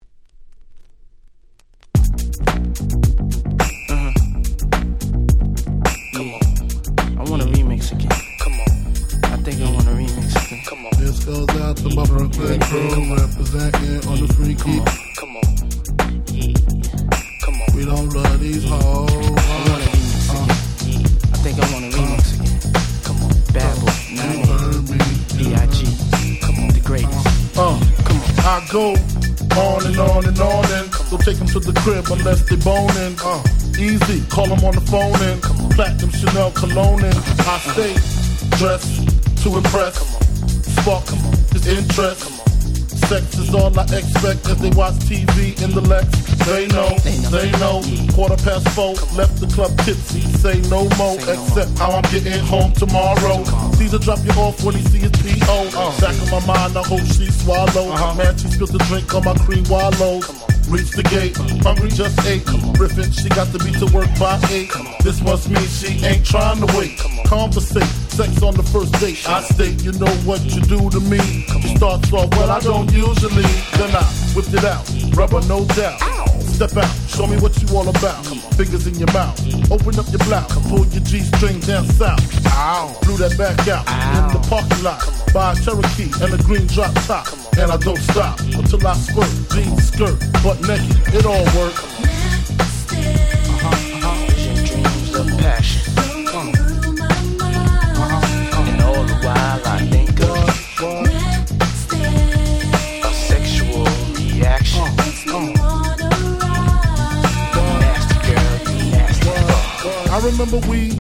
99' Super Hit Hip Hop !!